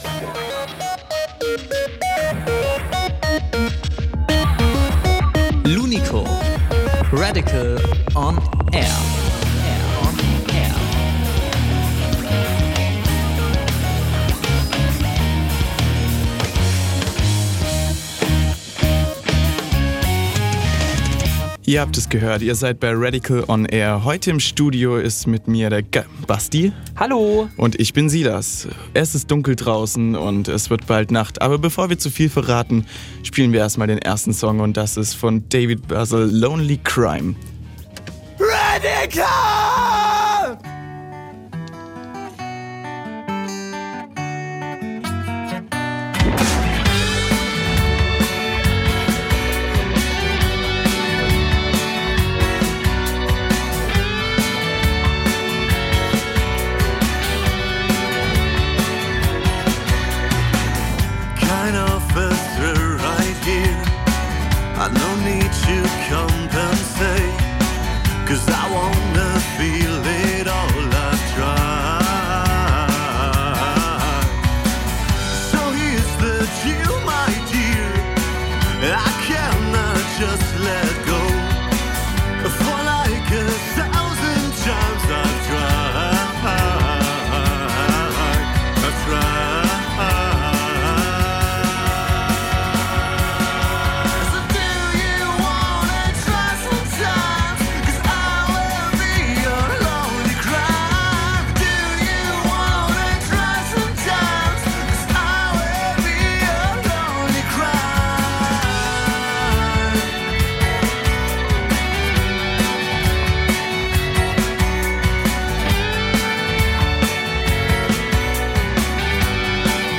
live im Studio THE BRIGHT NIGHTS